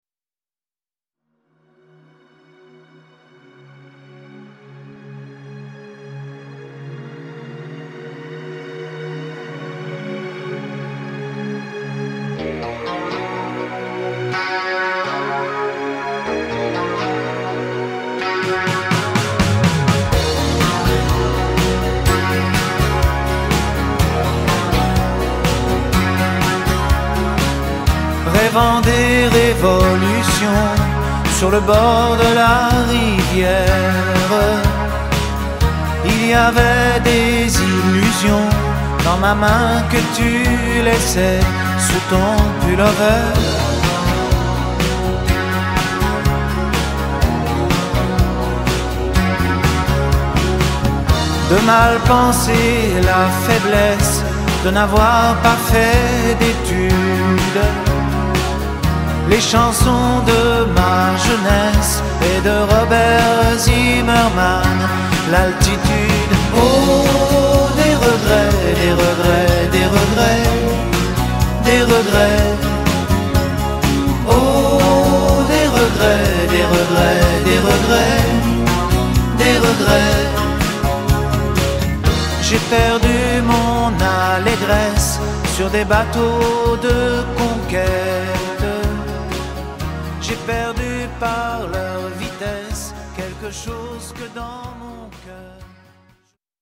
tonalité MIb mineur